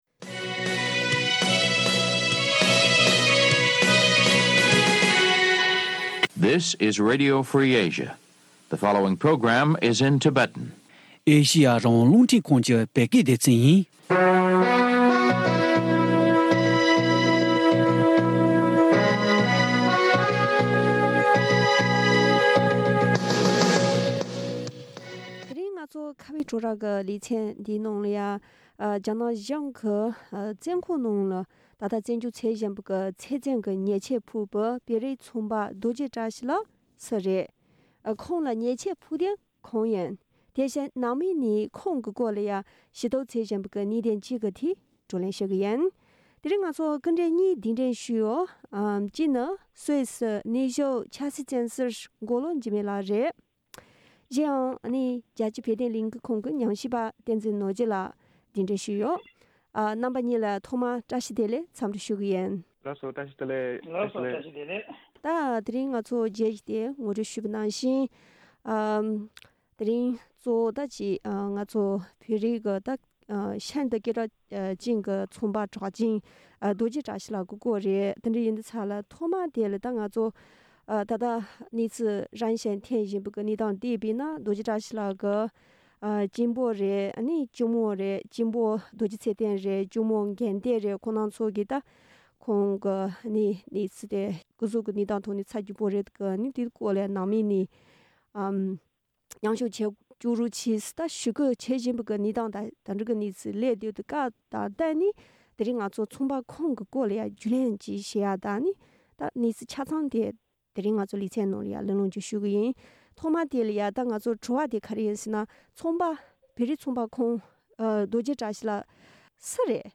གླེང་མོལ་ཞུས་པ་གསན་རོགས་གནང་།